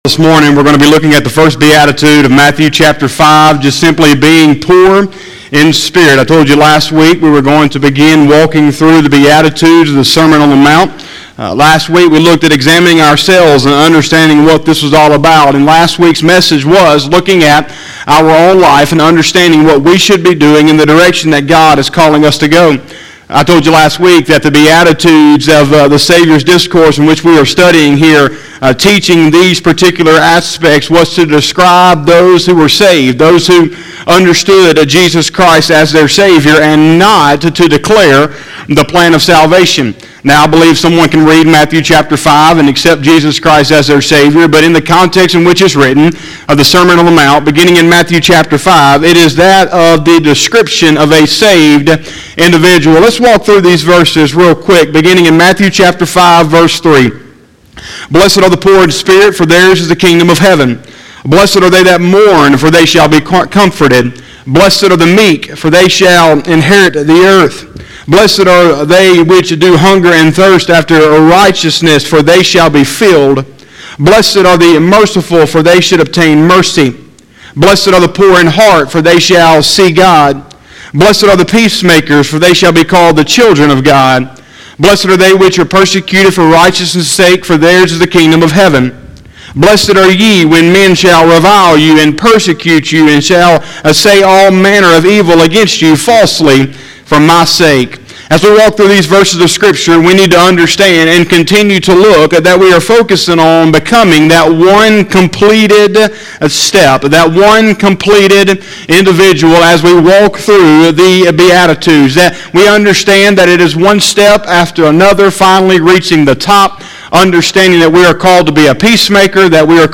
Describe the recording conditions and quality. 03/14/2021 – Sunday Morning Service